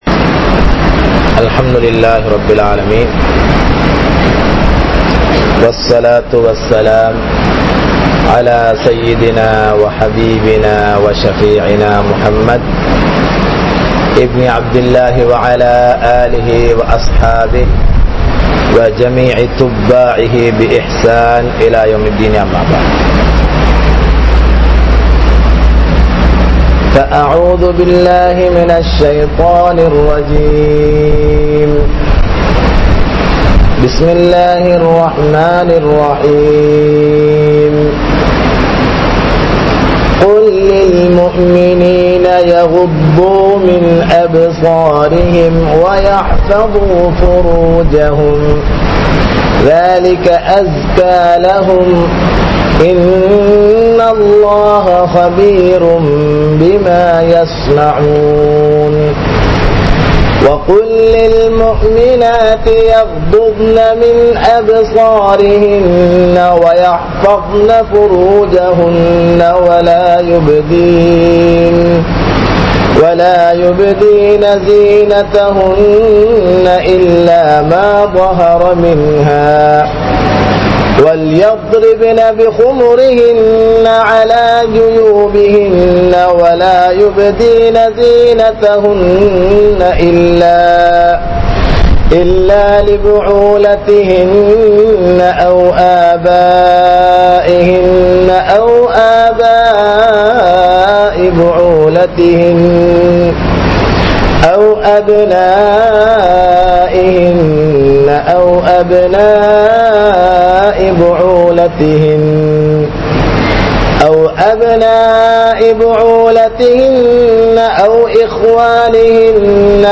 Veatkamum Hijabum (வெட்கமும் ஹிஜாபும்) | Audio Bayans | All Ceylon Muslim Youth Community | Addalaichenai
Dickwella, Muhideen Jumua Masjith